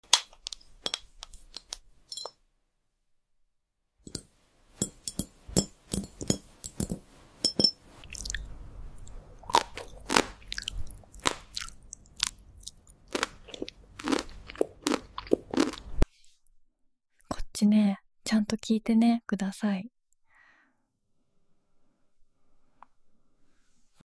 Tapping near her chest. Chewing